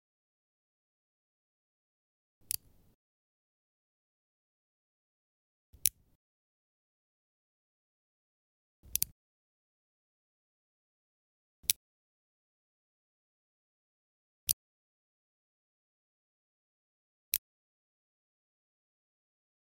开关声音 " 滚筒开关
描述：直插式灯线滚轮开关 Mic：Zoom SSH6Recorder：Zoom H6Format：Wav 48kHz，16bit，mono
Tag: 点击 S4F17 开关 关闭